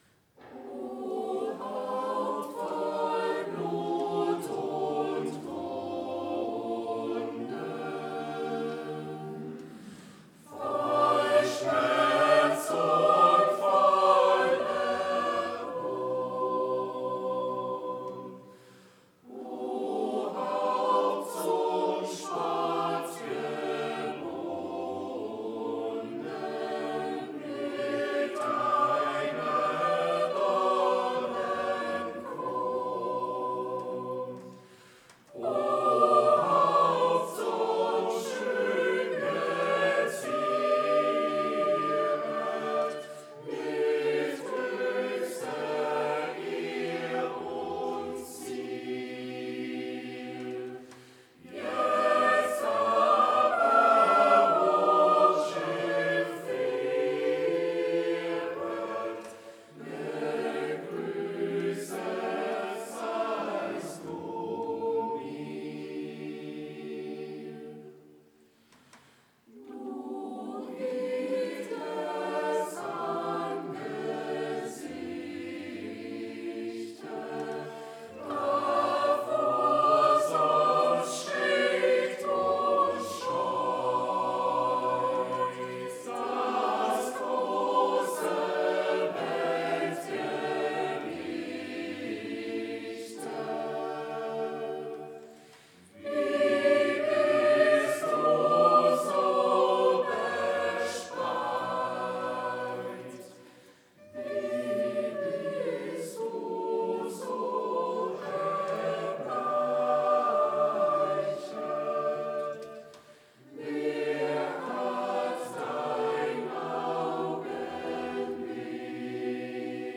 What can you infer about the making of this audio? Audiomitschnitt unseres Gottesdienstes am Palmsonntag 2025.